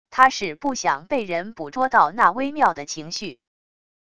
他是不想被人捕捉到那微妙的情绪wav音频生成系统WAV Audio Player